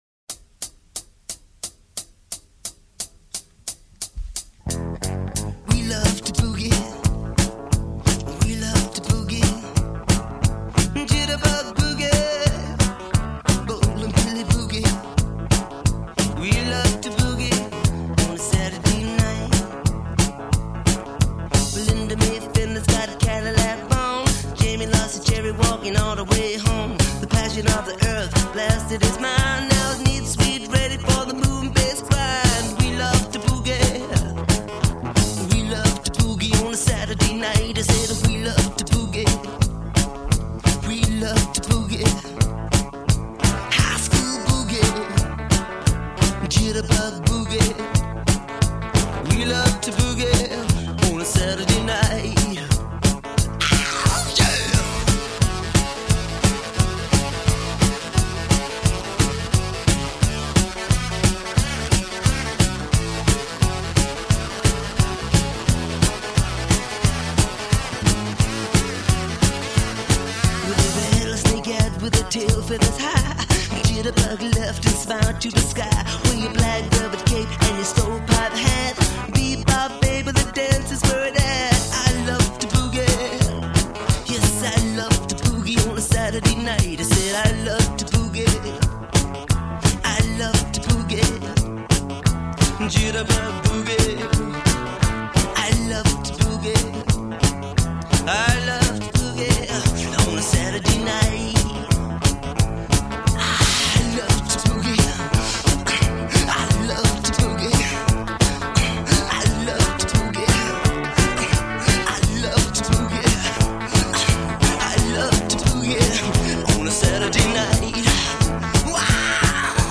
Music with a fast tempo: